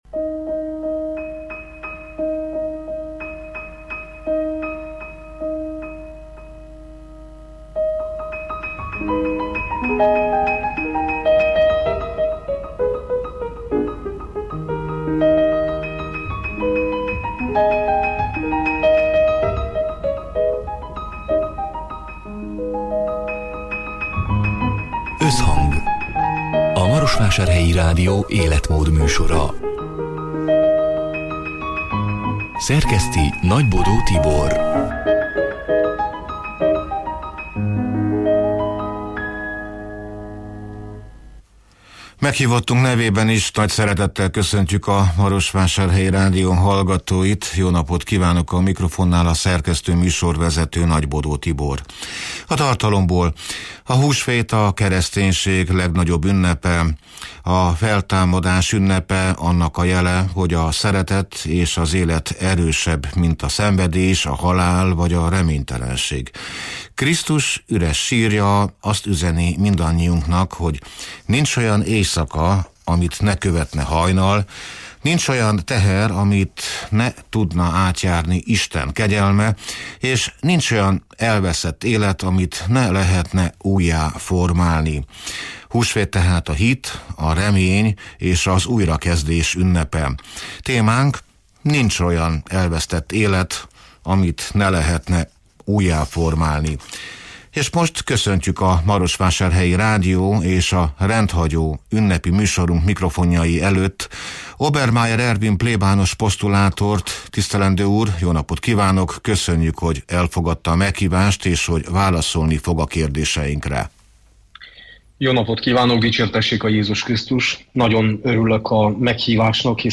(elhangzott: 2025. április 16-án, szerdán délután hat órától élőben)